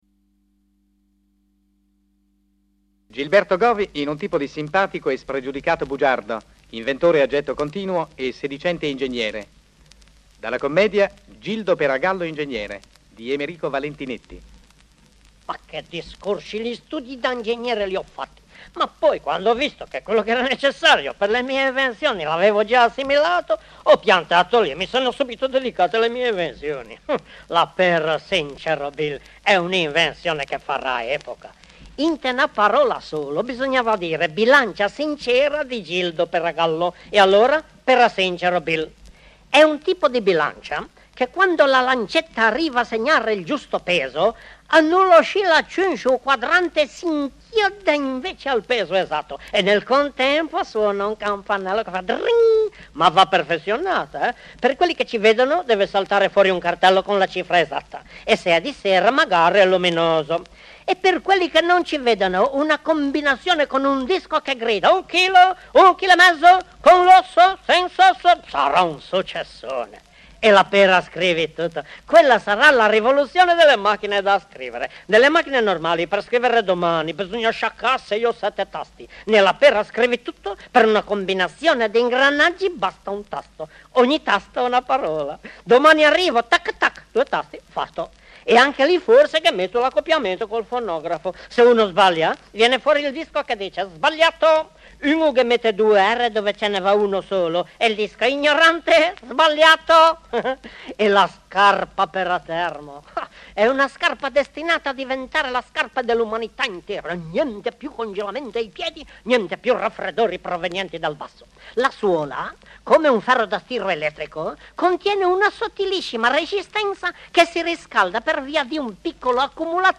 FOLKLORE ITALIANO - LIGURIA - RACCOLTA DI CANTI TRADIZIONALI E SCENE COMICHE